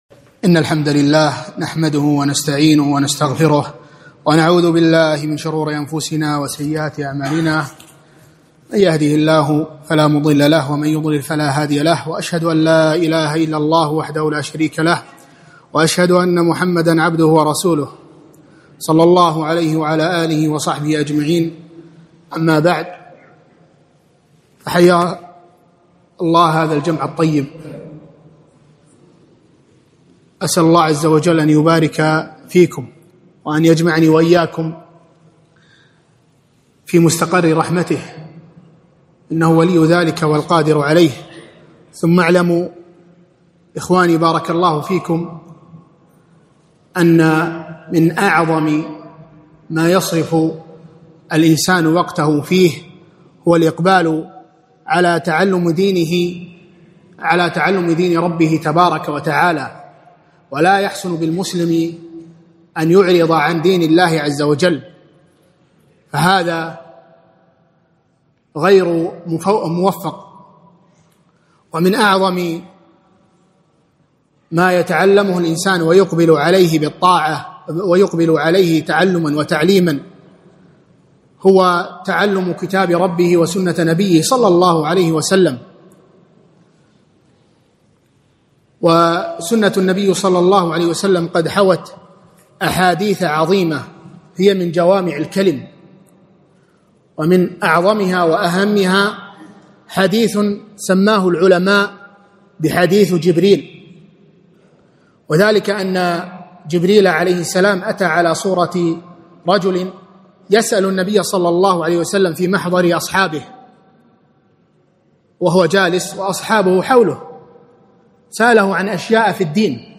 محاضرة - شرح حديث جبريل الطويل